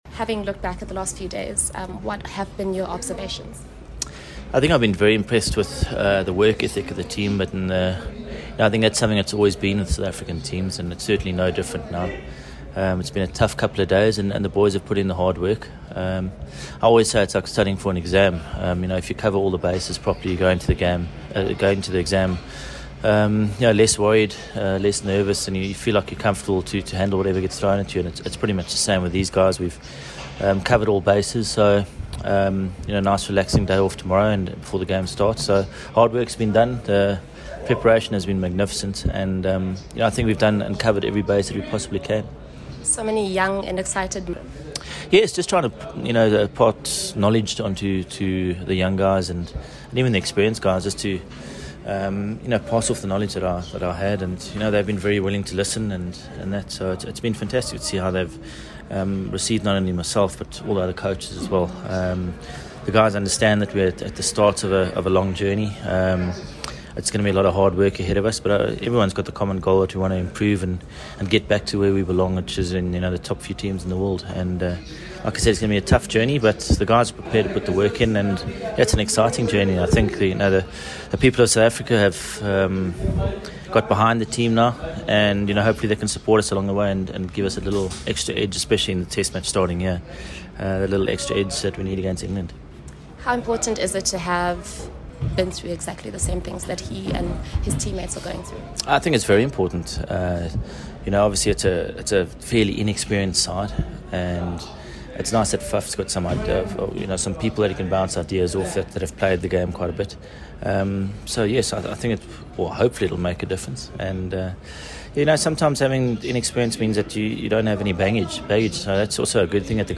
Jacques Kallis speaks to the media ahead of the Test series opener against England
Jacques Kallis addressed members of the media ahead of the Test series opener against England at SuperSport Park on Boxing Day (26 December)